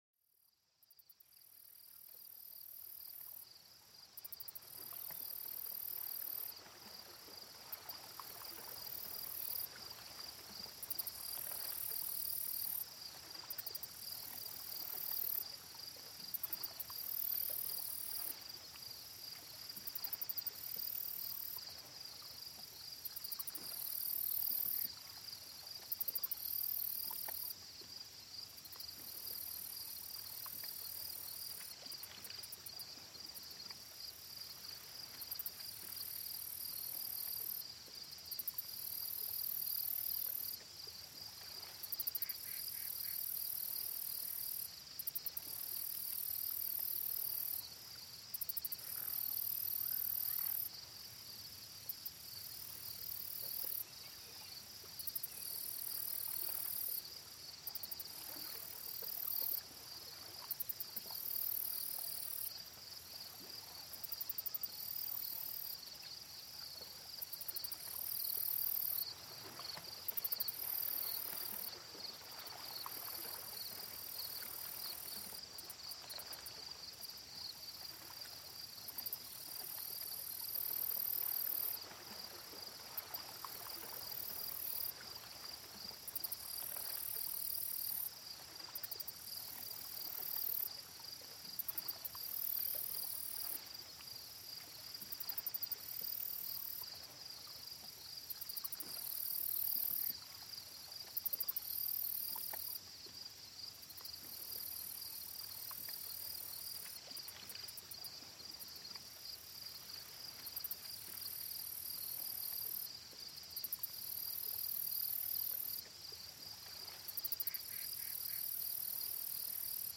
Plongez dans une ambiance sereine avec le doux bourdonnement des insectes au bord d'un lac paisible. Laissez-vous emporter par ces sons naturels qui apportent une sensation de calme et de relaxation profonde. Un épisode parfait pour déconnecter et favoriser un sommeil réparateur.Ce podcast vous emmène au cœur de la nature avec des enregistrements authentiques de sons naturels.